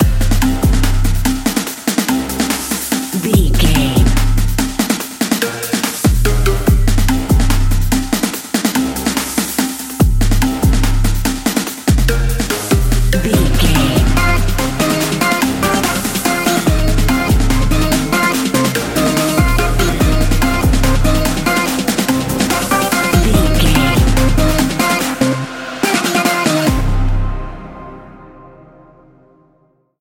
Epic / Action
Fast paced
Aeolian/Minor
Fast
synthesiser
drum machine
drums